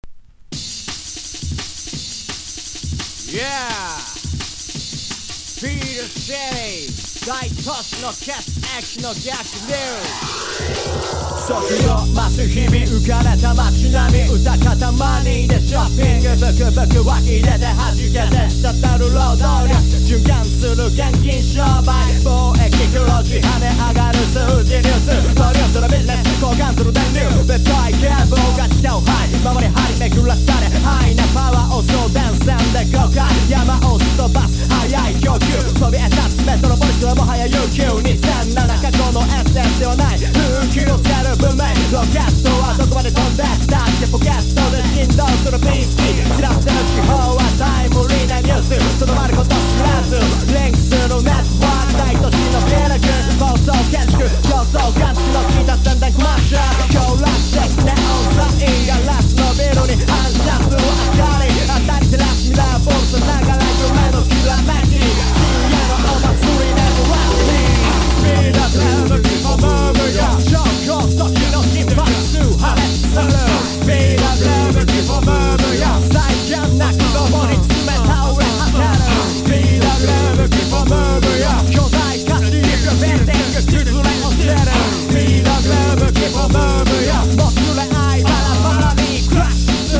• HIPHOP
UKブリストル直系のベース・サウンド！！